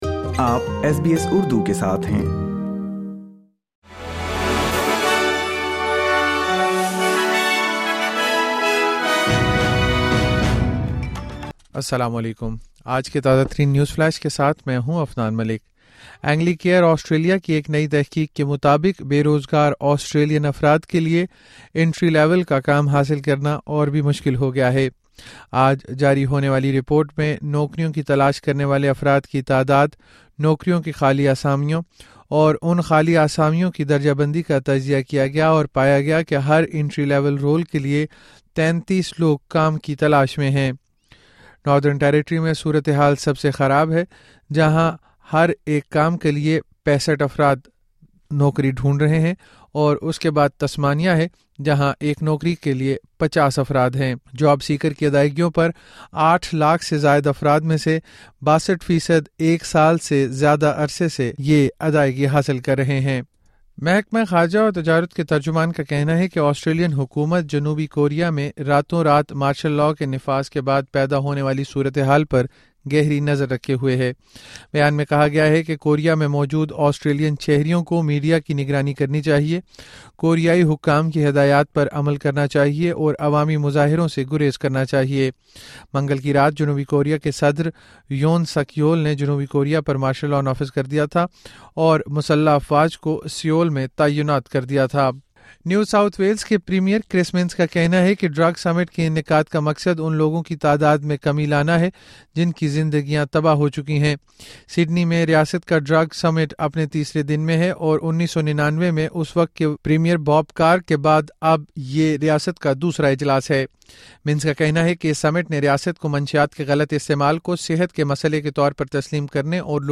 اردو نیوز فلیش:04 دسمبر 2024